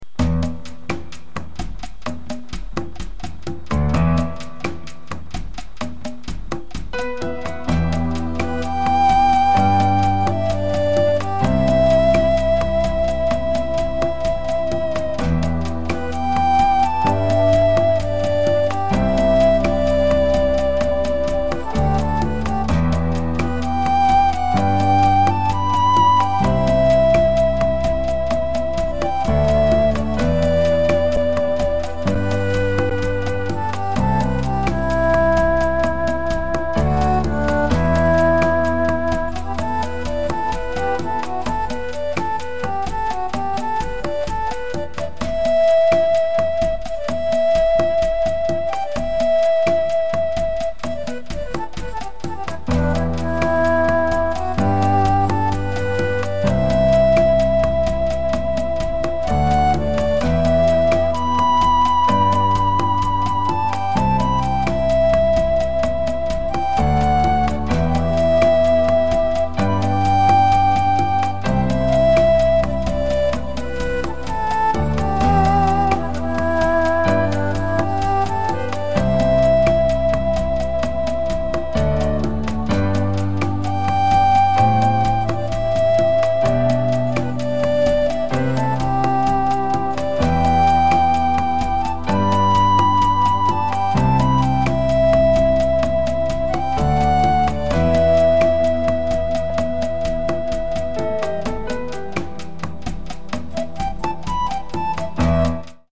02-P4-Triumph-of-spirit-Korean-Traditional-Music.mp3